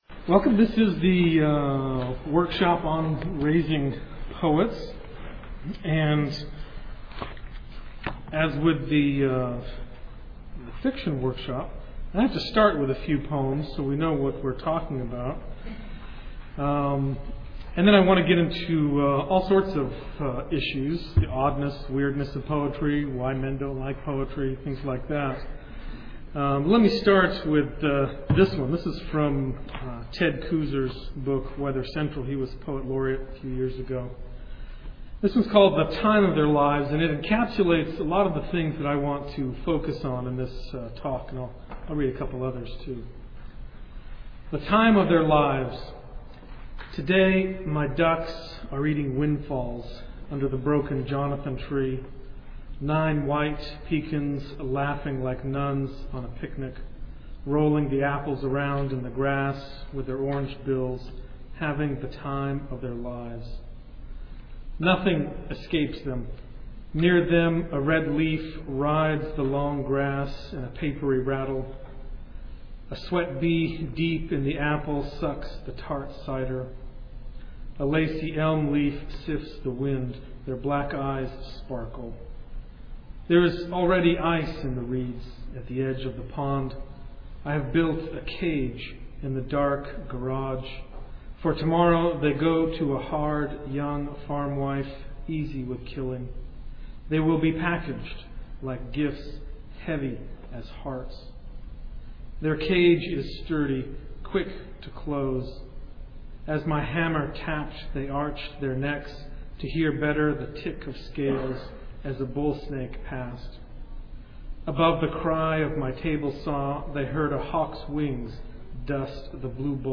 2007 Workshop Talk | 0:57:43 | K-6, Literature
Jan 31, 2019 | Conference Talks, K-6, Library, Literature, Media_Audio, Workshop Talk | 0 comments